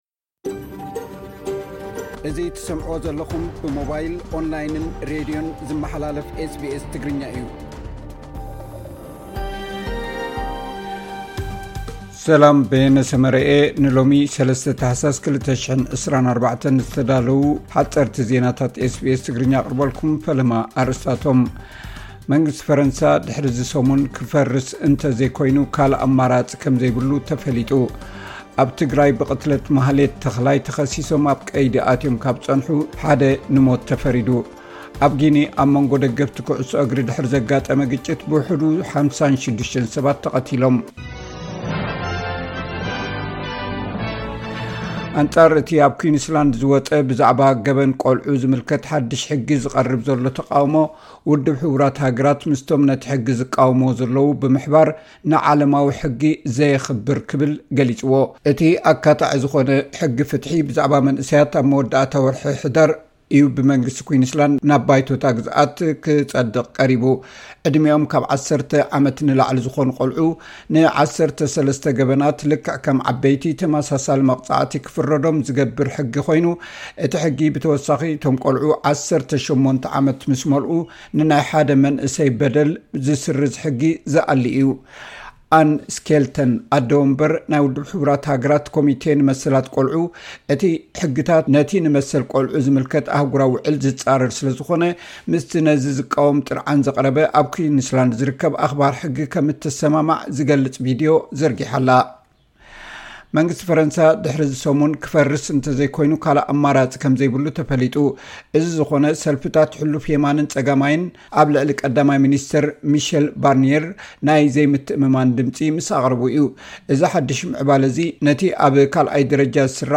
ሓጸርቲ ዜናታት ኤስ ቢ ኤስ ትግርኛ (03 ታሕሳስ 2024)